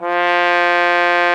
Index of /90_sSampleCDs/Roland L-CDX-03 Disk 2/BRS_Trombone/BRS_Tenor Bone 4